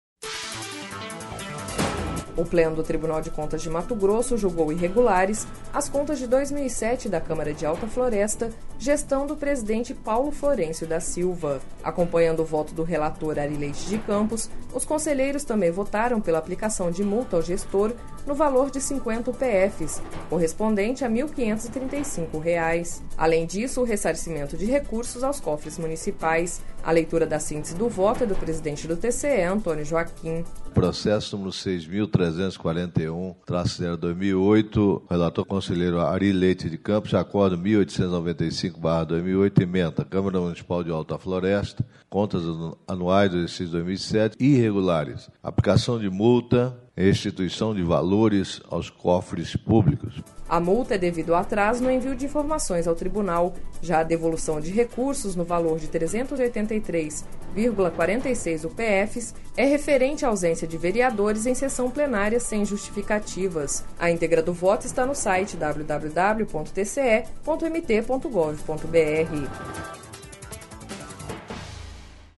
A leitura da síntese do voto é do presidente do TCE Antonio Joaquim.